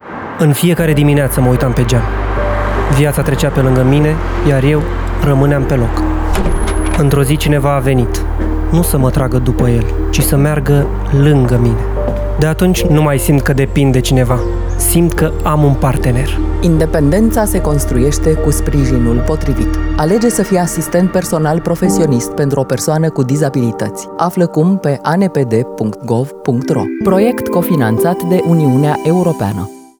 ANPDPD_spot-radio_final.mp3